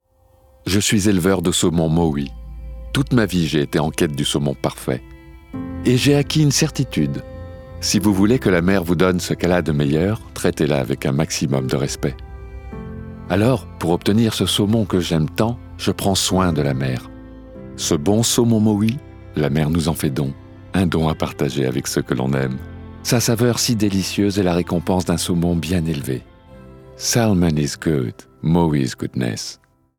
Voix off
Pub
- Baryton-basse